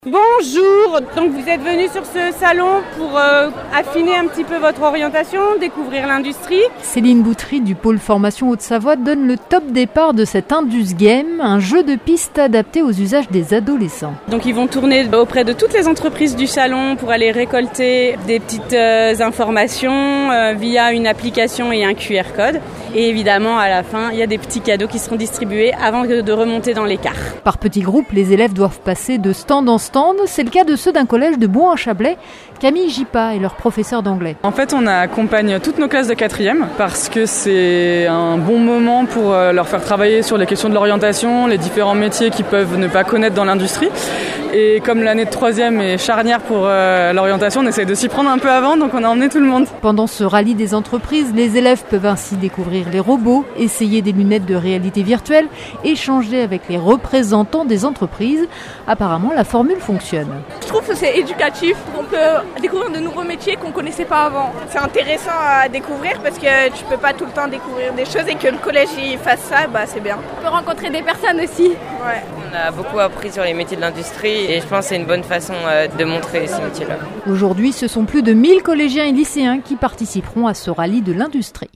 Explication avec ce reportage